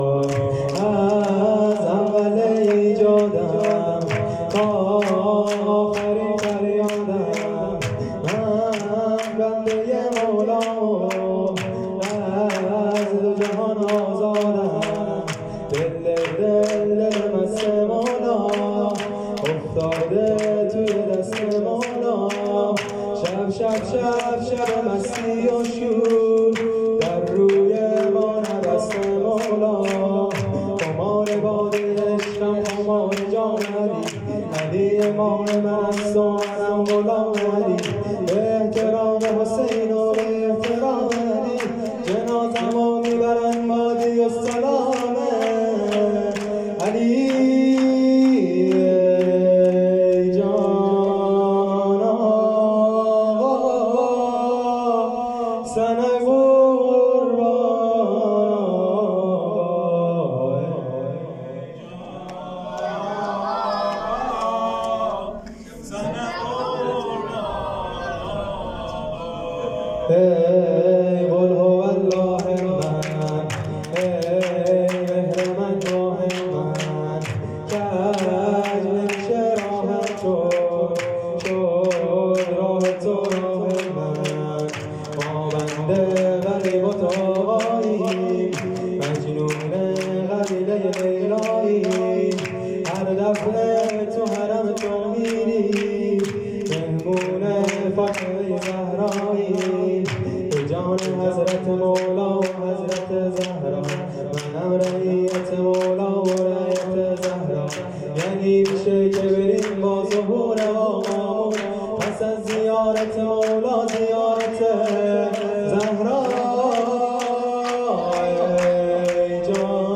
مولودی جشن ولادت پیامبر اکرم